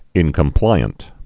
(ĭnkəm-plīənt)